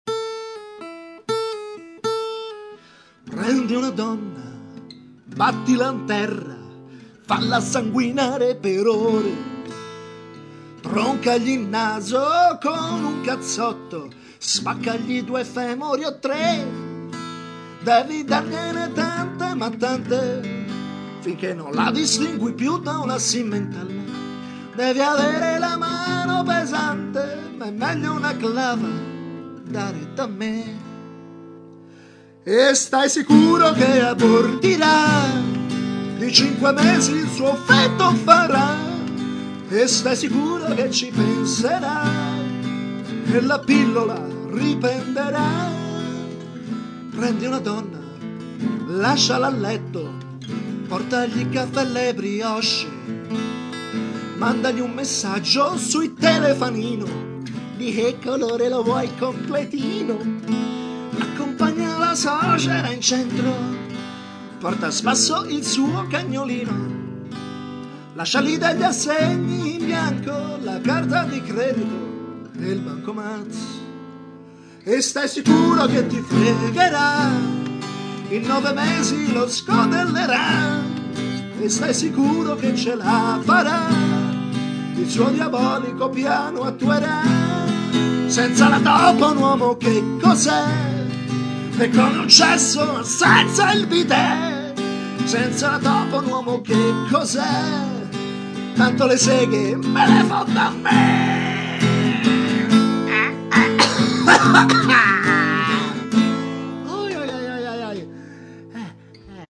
Un pezzo molto truce